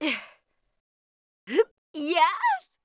fishing_catch_v.wav